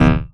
SCIFI_Energy_Pulse_03_mono.wav